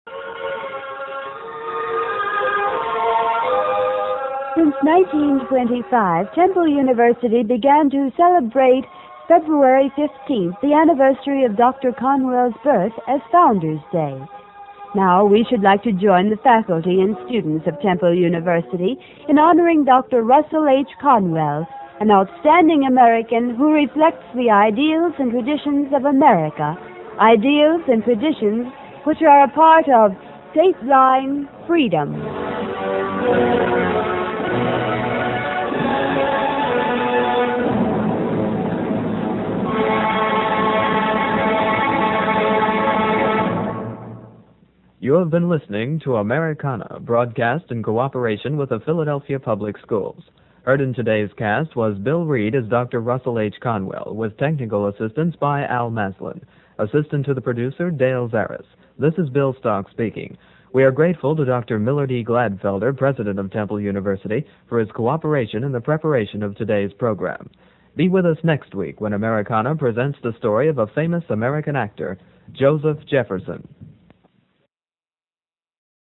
The shows were basically live-to-tape.